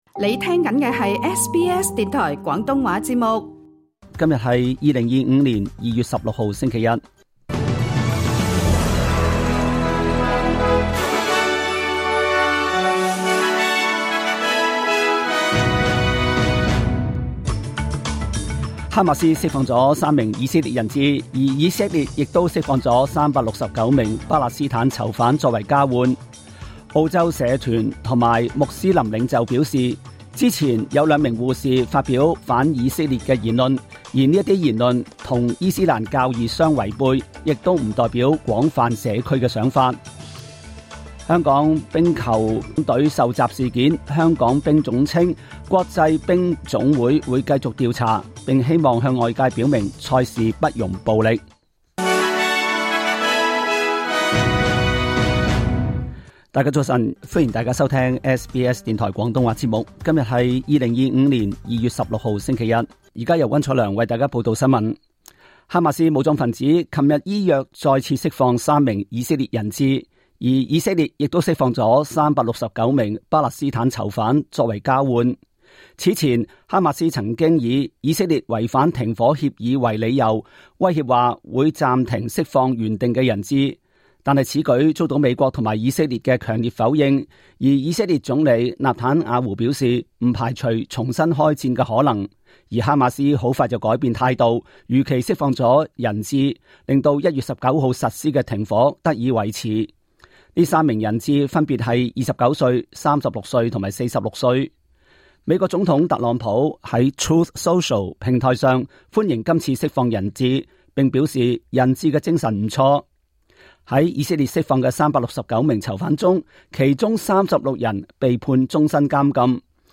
25 年 2 月 16 日 SBS 廣東話節目詳盡早晨新聞報道。